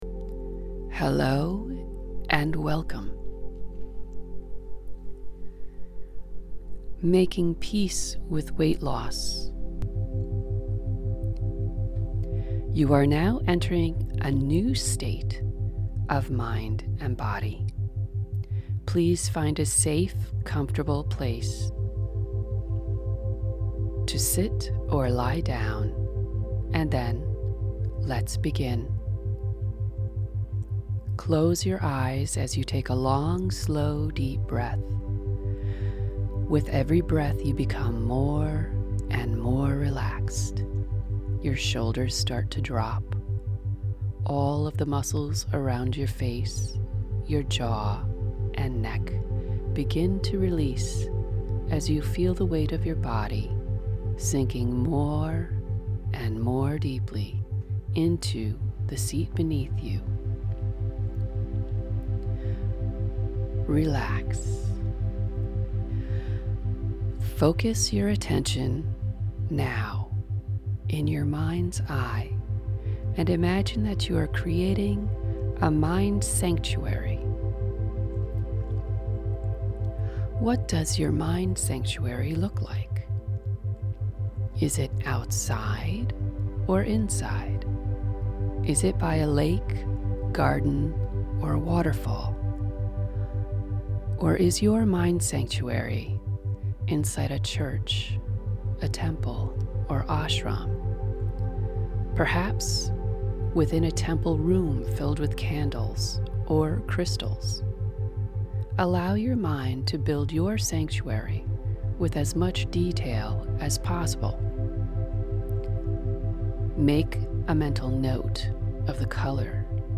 This is a progressive course that will help you begin to heal those emotions that are stopping you from your perfect health, and weight. This is the meditation that corresponds the most with what you experienced in your body scan.